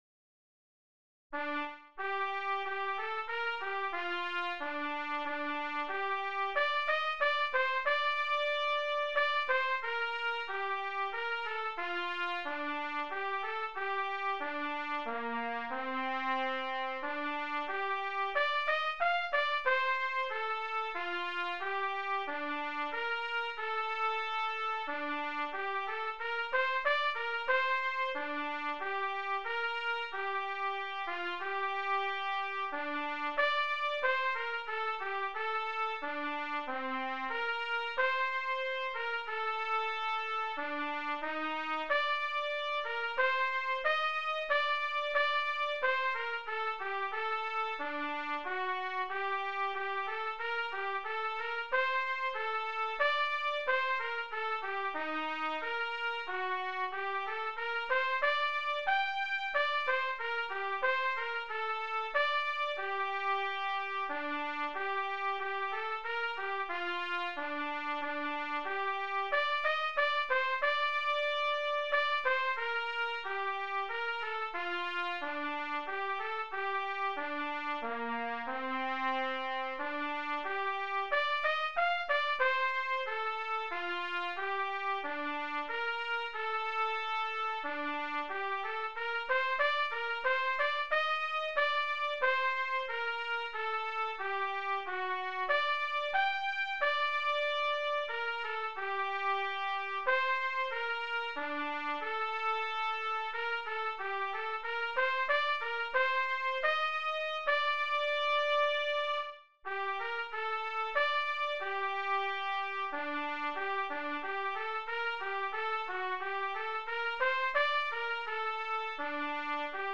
DIGITAL SHEET MUSIC - TRUMPET SOLO
Sacred Music, Hymns, Unaccompanied Solo